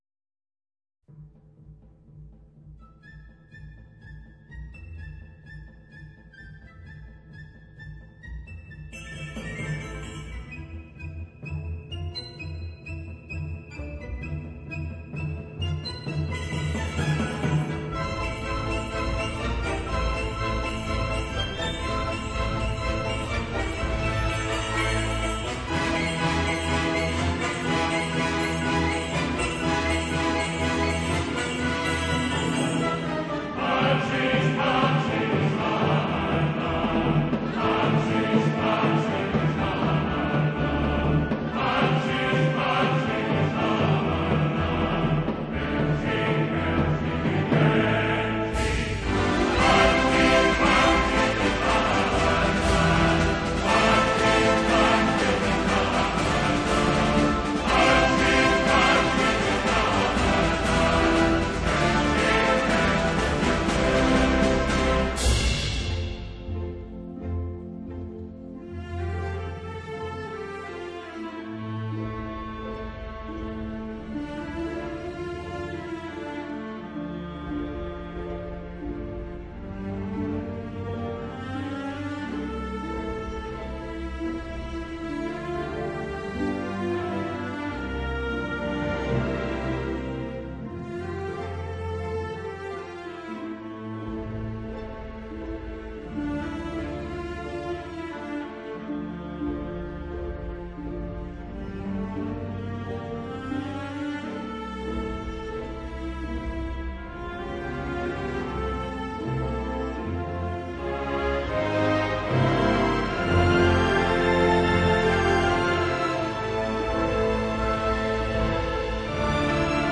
交响曲 资源格式
如春风般柔和的旋律，温顺而优雅，仿佛一位美丽而端庄的公主在待从的拥簇下经过市集。
丰厚的堂音把所有乐器、人声包裹着，声音层次丰富而乐器大小比例恰当。1977年的模拟录音技术，已臻成熟的最高境界。